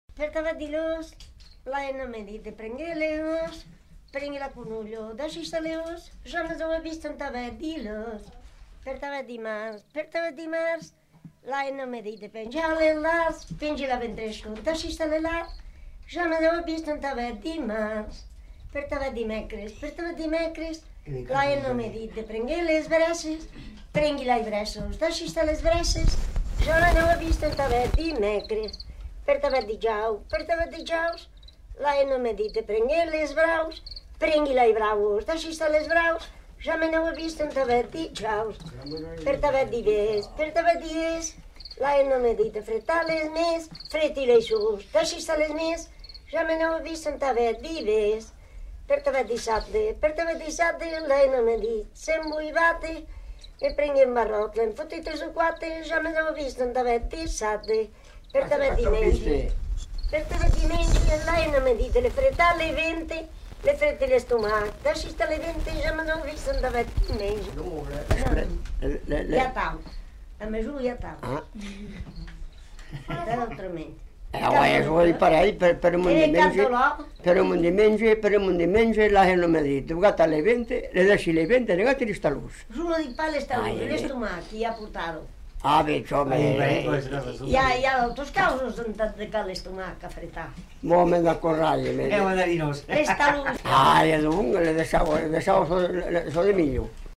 Lieu : Puylausic
Genre : chant
Effectif : 1
Type de voix : voix de femme
Production du son : chanté
Classification : énumératives diverses